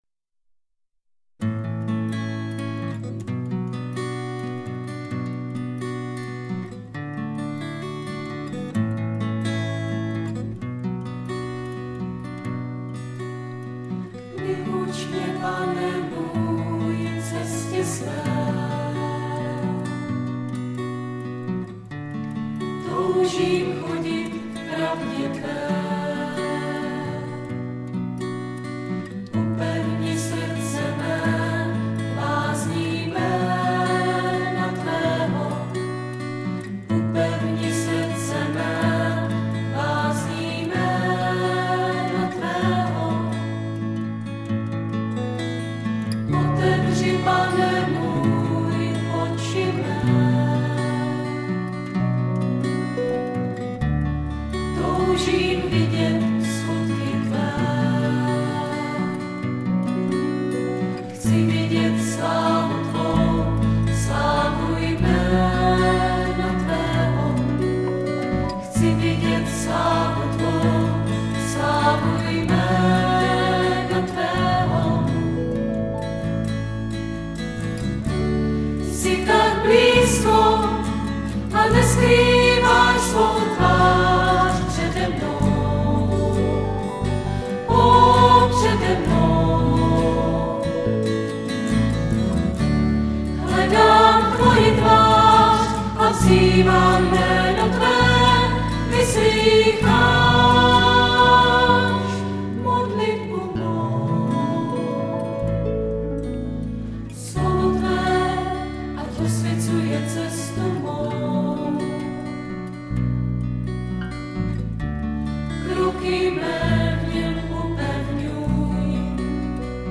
Nahráno 24.-26. června 2003 v modlitebně CČSH v Kroměříži.
Zpěv
Klíčová slova: písně, schóla,